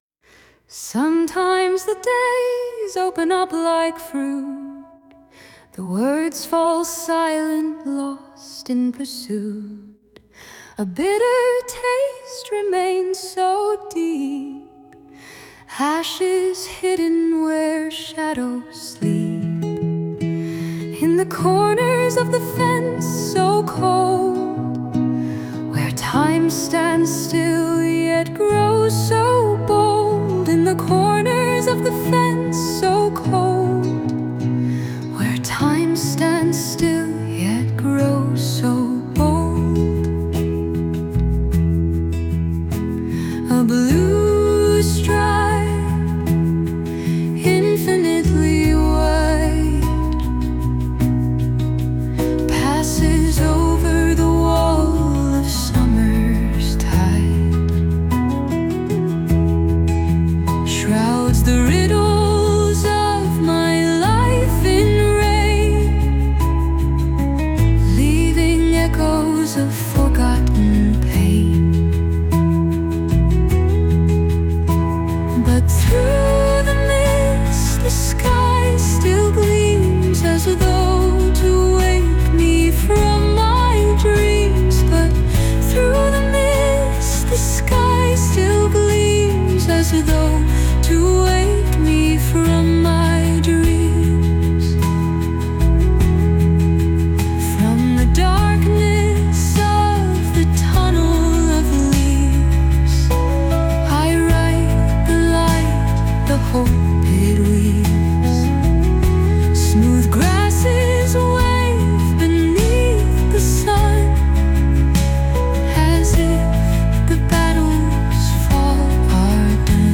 Die musikalische Realisation erfolgt mithilfe von künstlicher Intelligenz, wobei die genauen Quellen den jeweiligen Songseiten hinzugefügt werden.